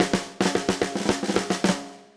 Index of /musicradar/80s-heat-samples/110bpm
AM_MiliSnareA_110-02.wav